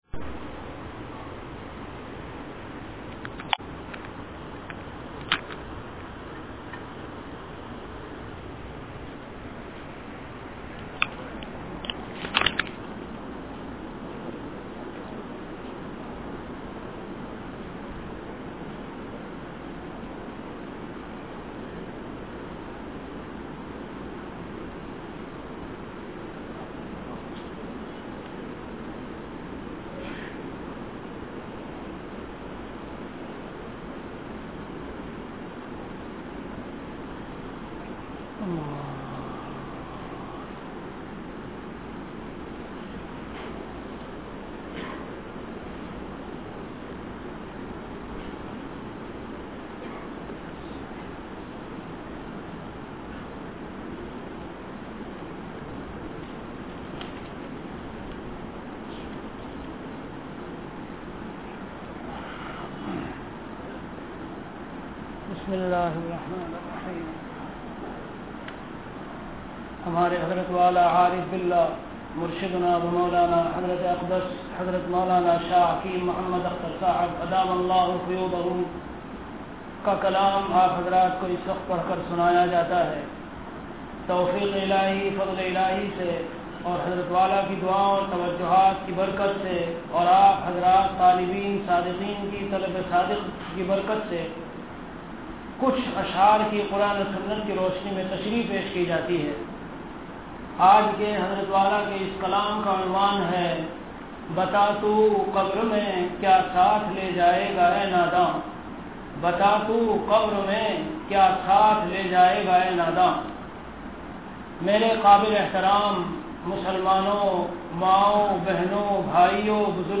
Delivered at Khanqah Imdadia Ashrafia.
Bayanat · Khanqah Imdadia Ashrafia